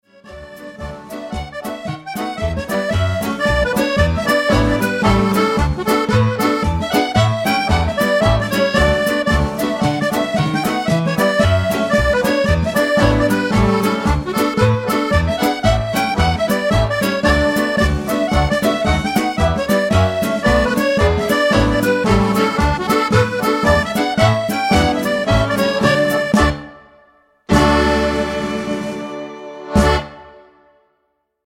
8 x 40 Jig